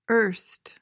PRONUNCIATION: (uhrst) MEANING: adverb: Formerly: in the past.